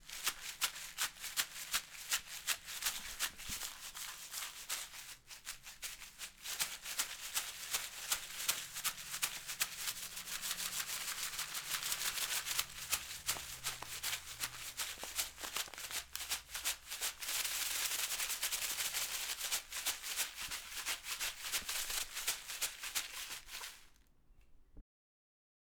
maracas 2.wav